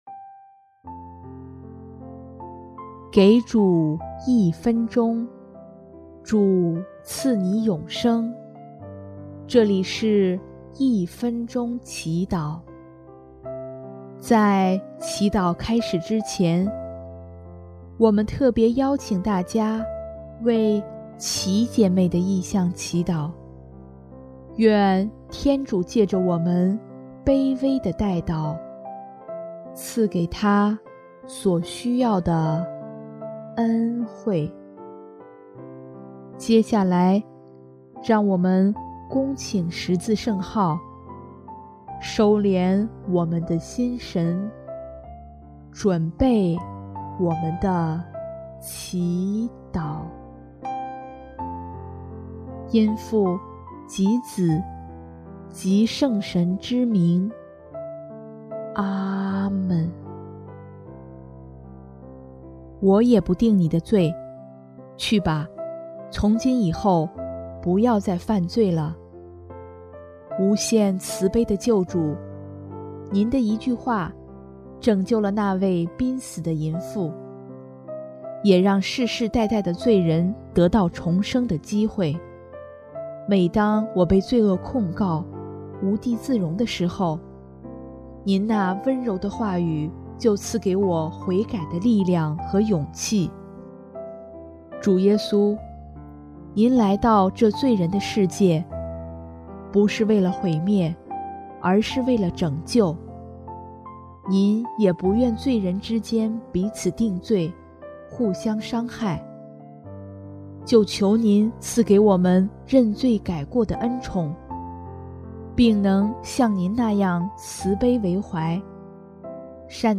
【一分钟祈祷】|3月18日 求您赐给我们认罪改过的恩宠